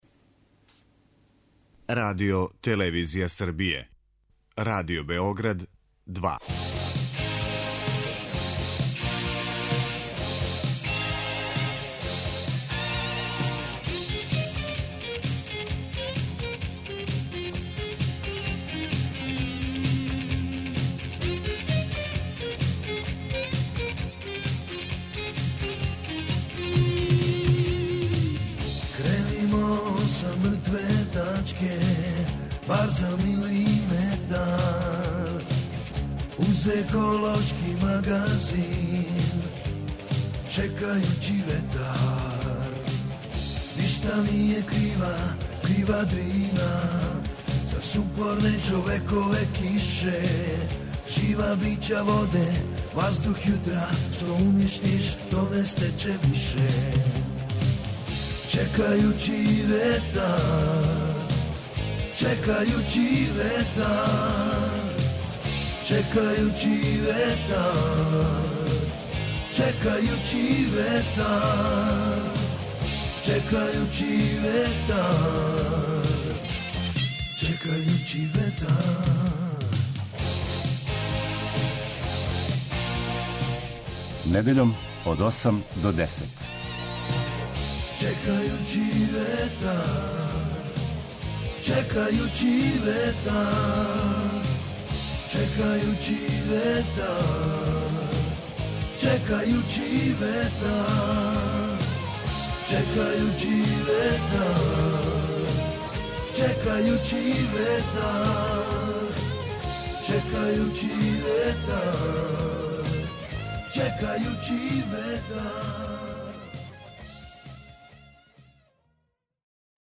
Чекајући ветар - еколошки магазин